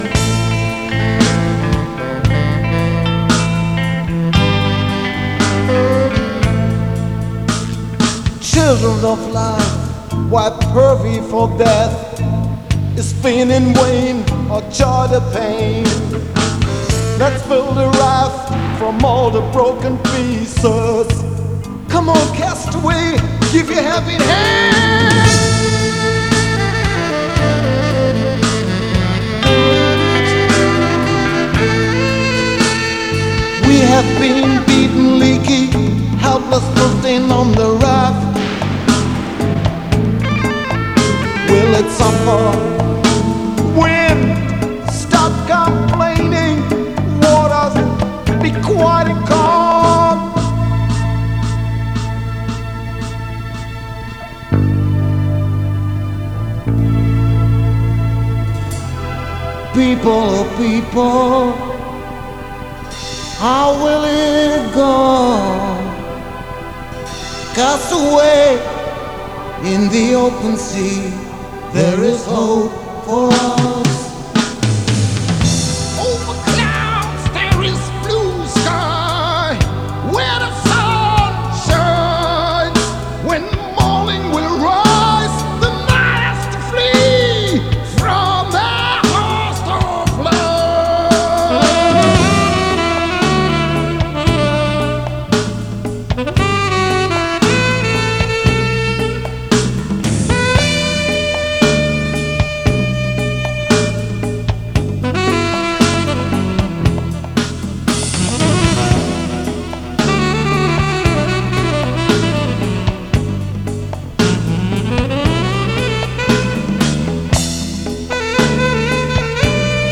git, keyboards
vocals
sax
drums